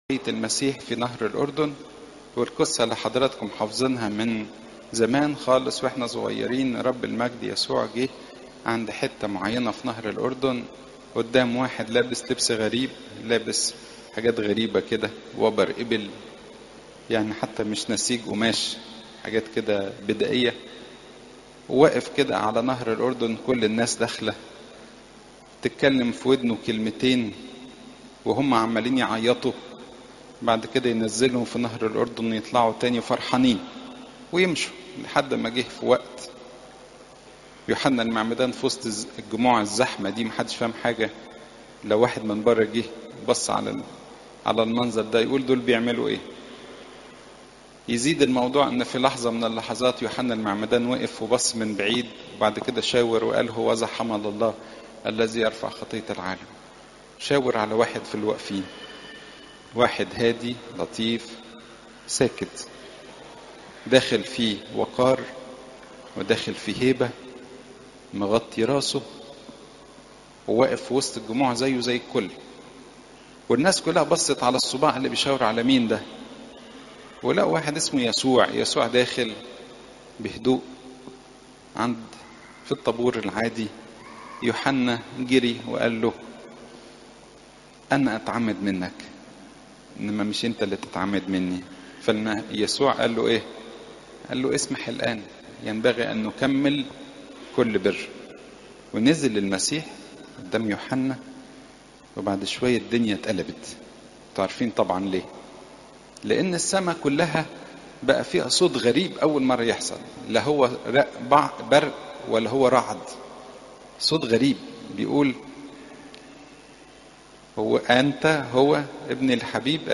عظات المناسبات برامون الغطاس (لو 3 : 1 - 18)